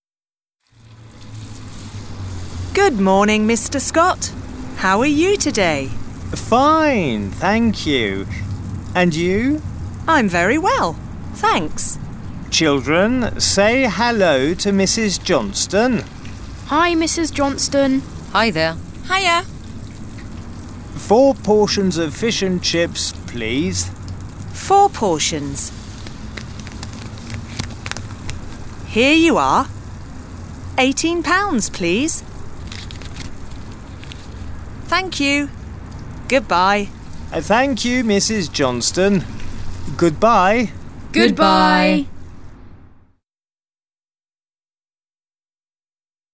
Introduction_sequence-fish_and_chip_shop.wav